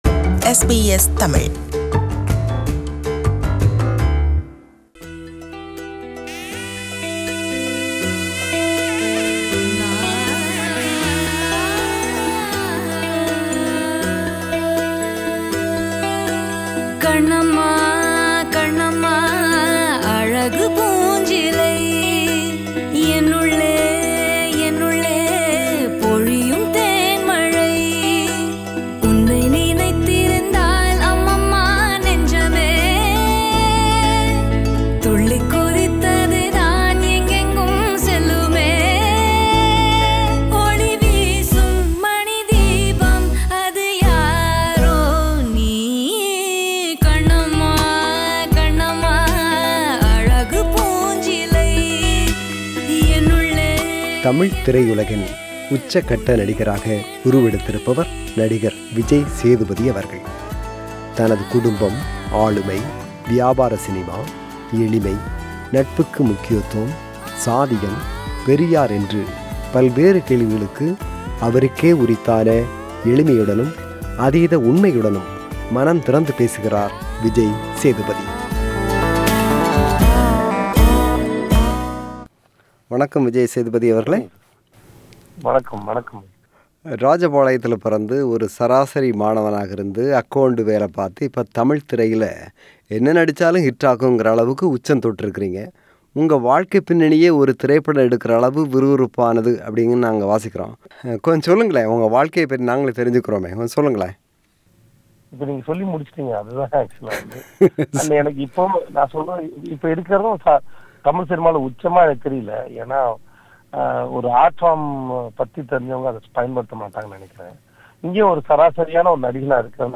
Interview with Vijay Sethupathi - Part 1